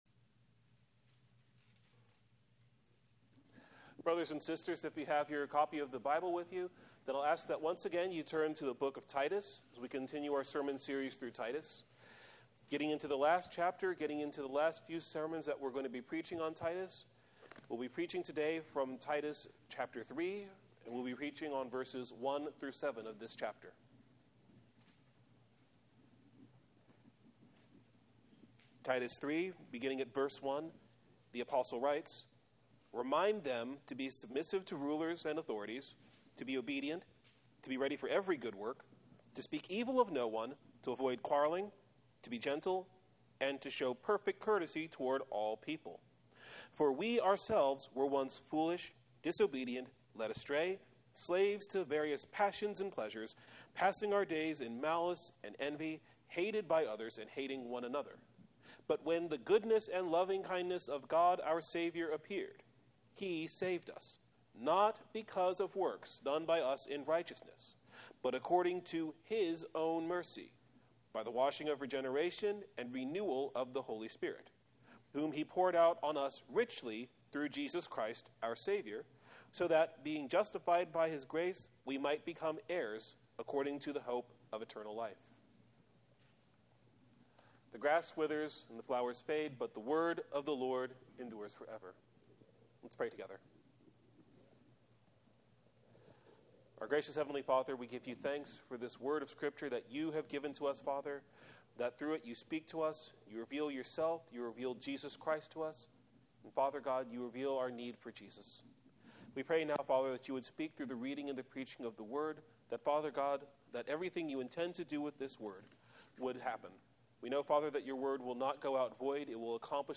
Sermon Text: Titus 3:1-7 Theme: The Christian must show every kindness to every person because he has been saved from all his sins by the merciful, unmerited kindness of the Lord through Jesus Christ.